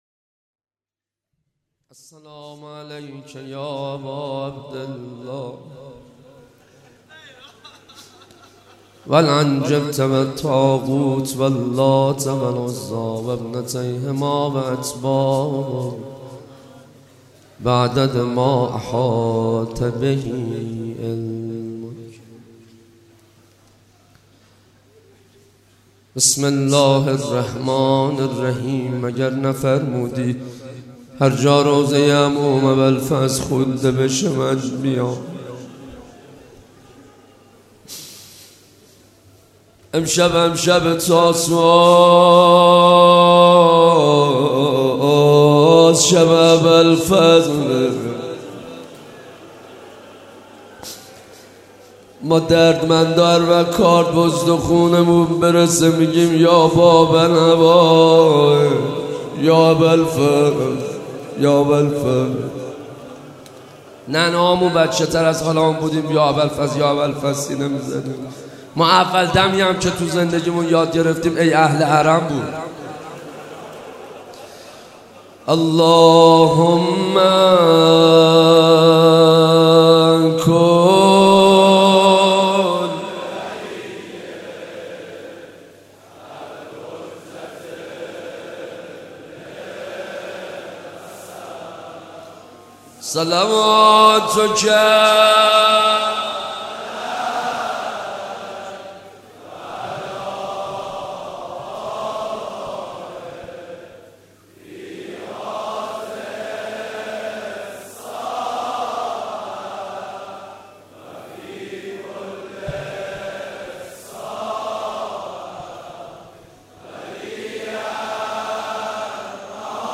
مناسبت : شب هشتم محرم
قالب : روضه